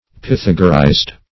Search Result for " pythagorized" : The Collaborative International Dictionary of English v.0.48: Pythagorize \Py*thag"o*rize\, v. i. [imp.